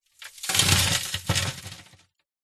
Похороны гроба, мелкая пыль, грязь на крышке, запись изнутри от первого лица, одиночное действие 2